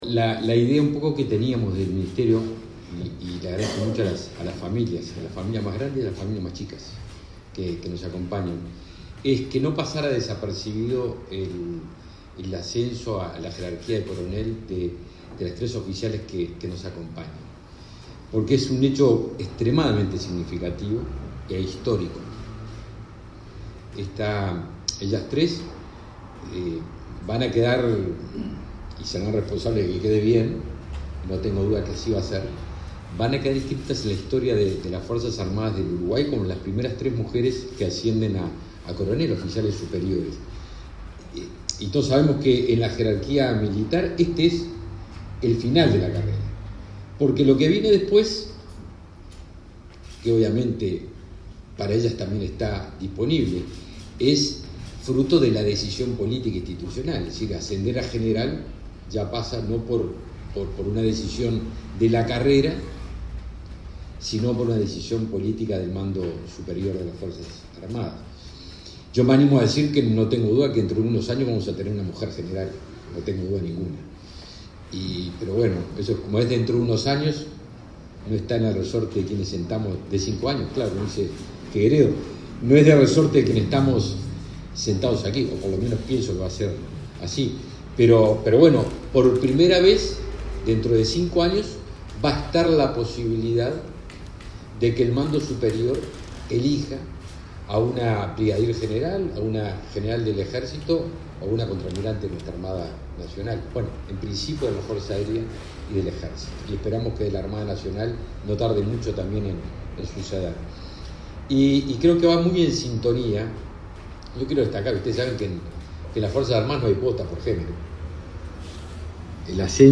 Palabras del ministro de Defensa Nacional, Javier García
El ministro de Defensa Nacional, Javier García, participó, este viernes 8, en el acto de reconocimiento a las tres primeras oficiales de las Fuerzas